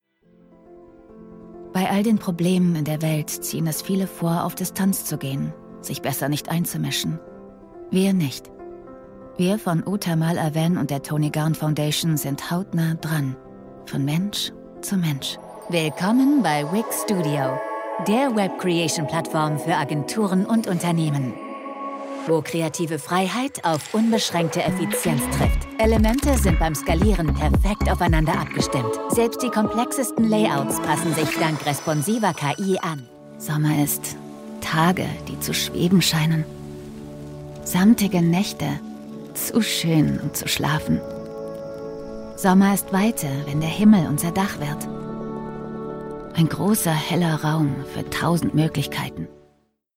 Female
Approachable, Assured, Authoritative, Bright, Character, Confident, Conversational, Cool, Corporate, Energetic, Engaging, Friendly, Natural, Posh, Reassuring, Smooth, Soft, Upbeat, Versatile, Warm
Microphone: Austrian Audio OC18